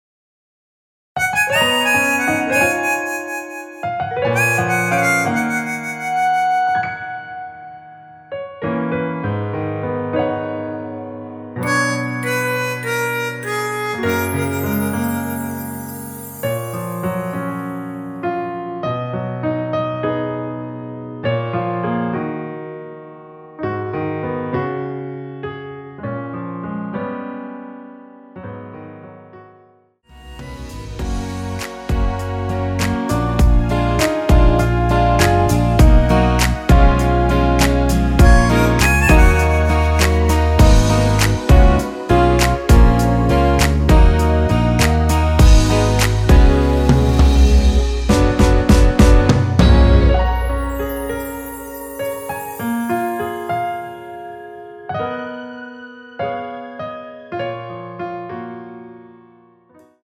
원키에서(-1)내린(1절+후렴)MR입니다.
F#
앞부분30초, 뒷부분30초씩 편집해서 올려 드리고 있습니다.
중간에 음이 끈어지고 다시 나오는 이유는